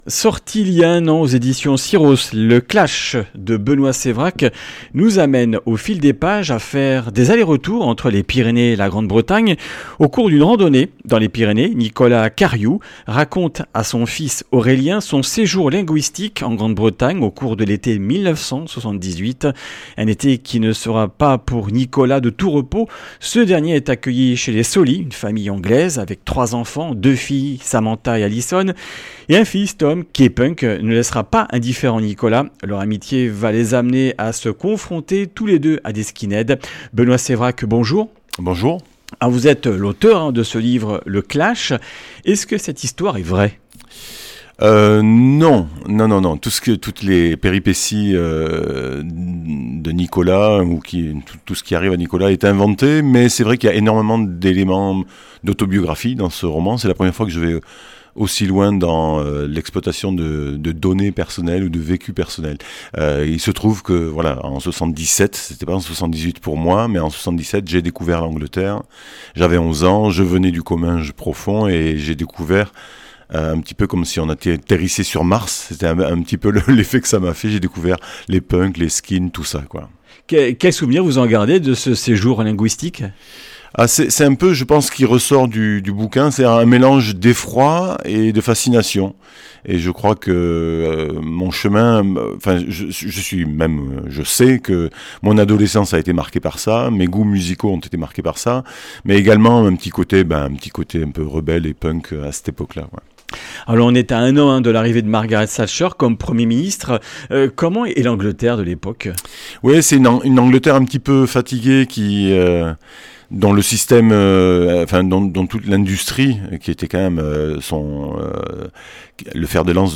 Comminges Interviews du 04 févr.
[ Rediffusion ]